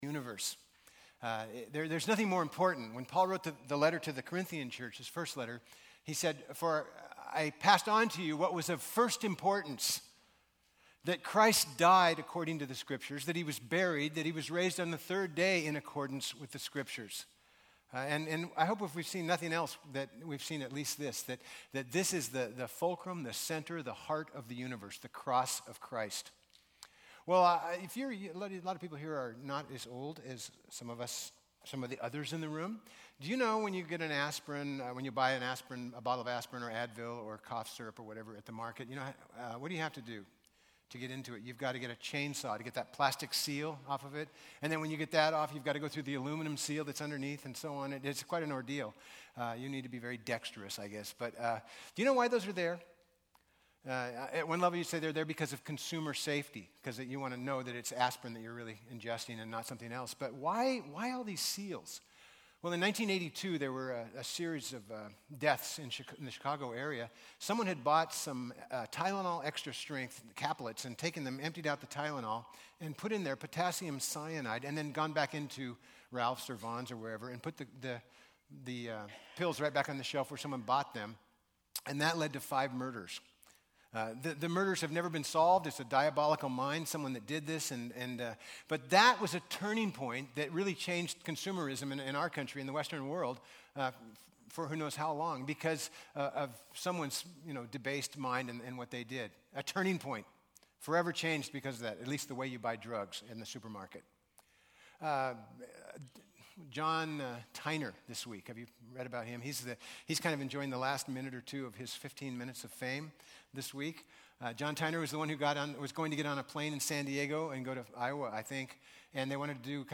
Hebrews 10:1-25 Service Type: Sunday Hebrews 10:1-25 « A Justification